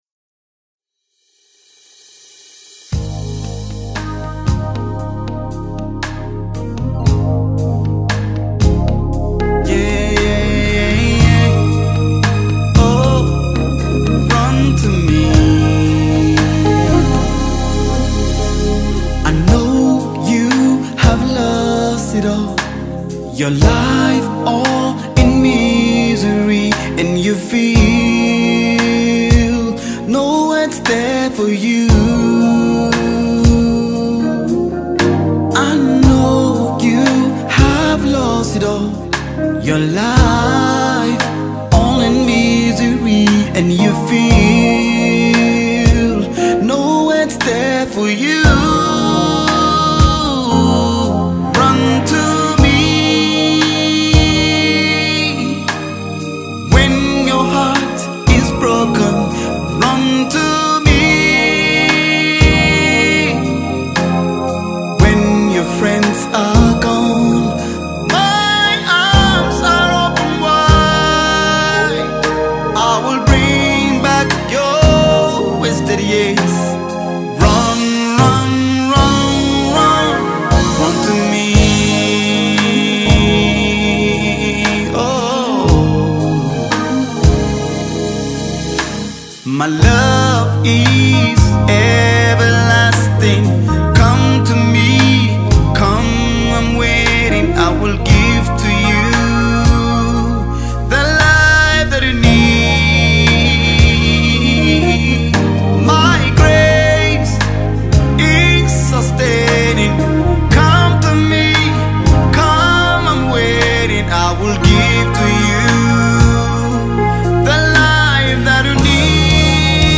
AlbumsMUSICNaija Gospel Songs